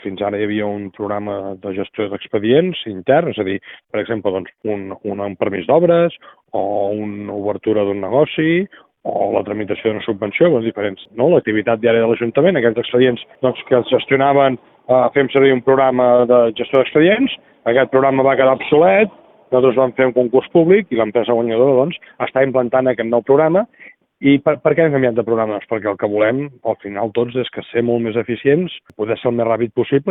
L’alcalde Marc Buch ha indicat que, amb el canvi del programa de gestió interna, es guanyarà en rapidesa per tal d’oferir el millor servei a la ciutadania. Són declaracions a Ràdio Calella TV.